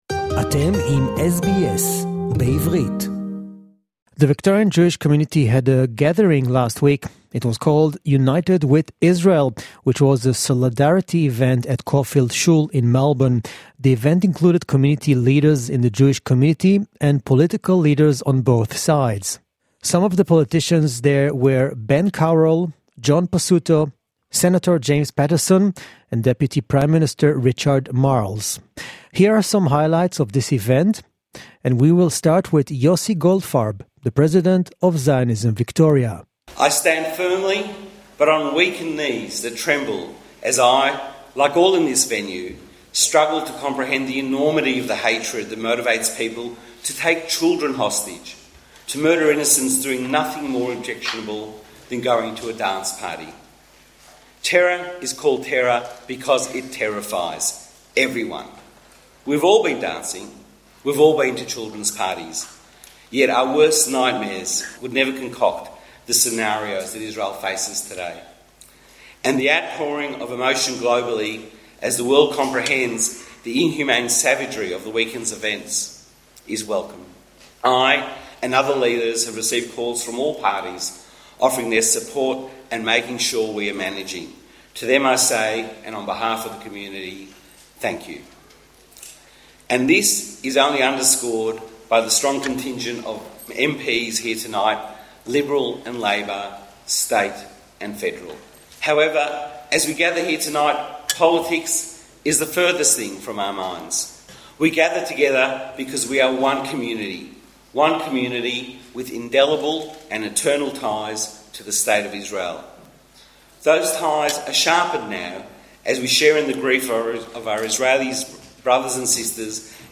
A Solidarity Community Event at Caulfield Shule in Melbourne, with speeches from politicians and community leaders. Some of the members of parliament included Ben Carroll, John Pesutto, Senator James Paterson and Deputy Prime Minister Richard Marles